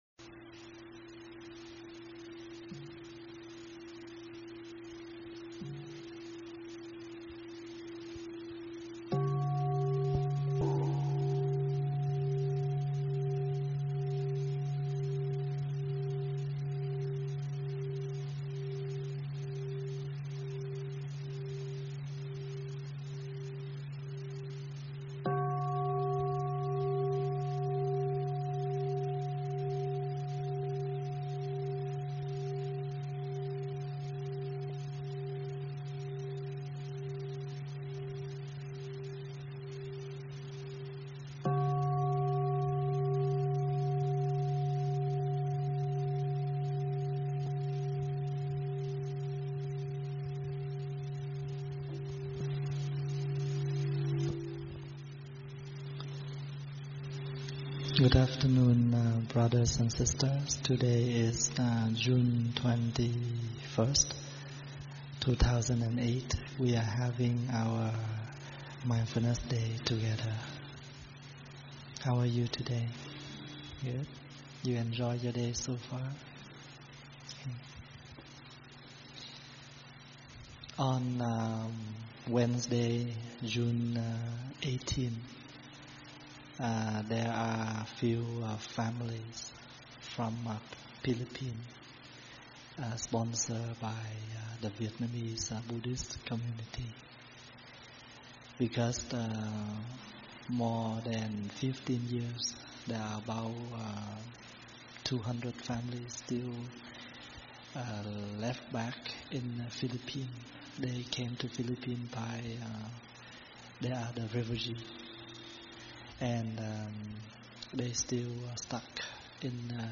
thuyết pháp Qualities of a Good Practitioner
giảng tại tu viện Tây Thiên